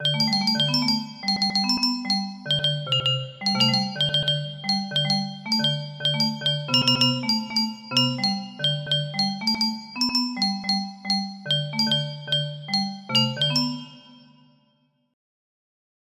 21002 music box melody